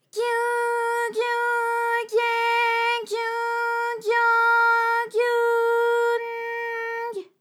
ALYS-DB-001-JPN - First Japanese UTAU vocal library of ALYS.
gyu_gyu_gye_gyu_gyo_gyu_n_gy.wav